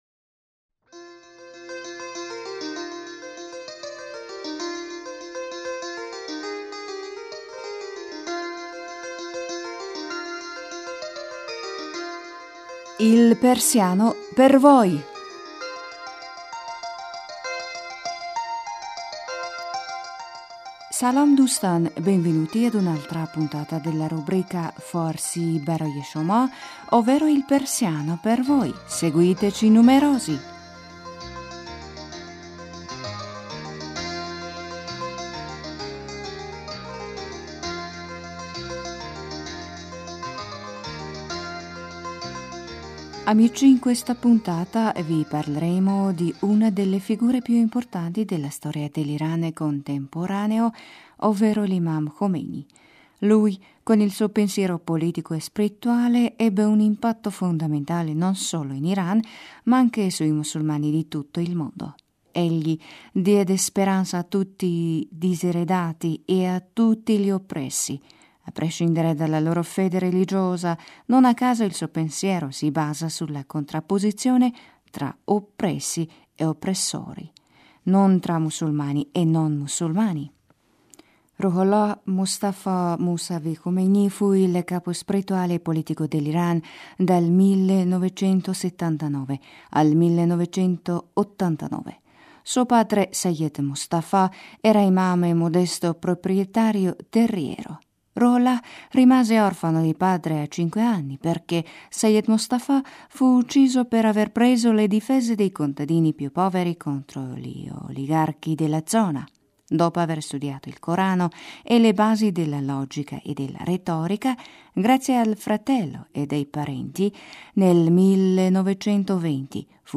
Ora vi invitiamo ad ascoltare la conversazione tra Paolo e Ramin: